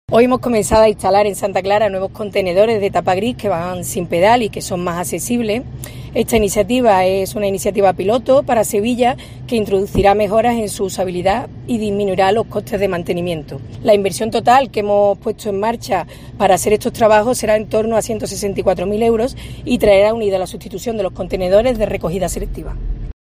La delegada de Limpieza anuncia la renovación de los contenedores